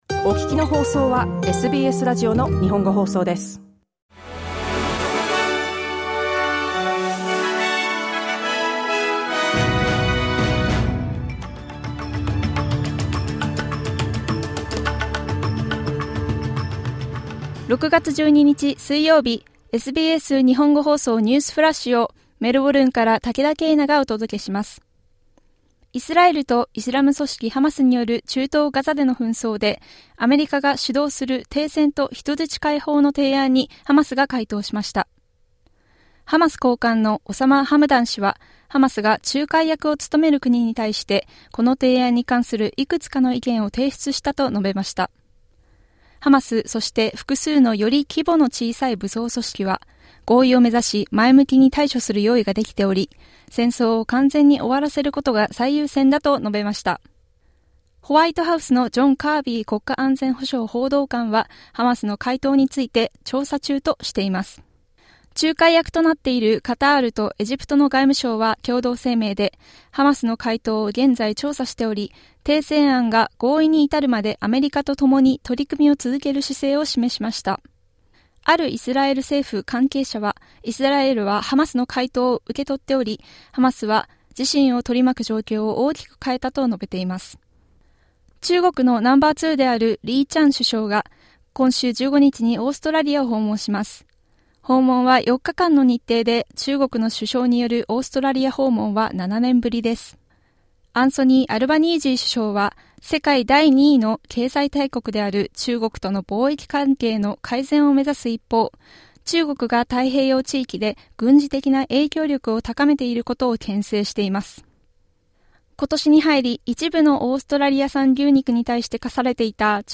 SBS日本語放送ニュースフラッシュ 6月12日水曜日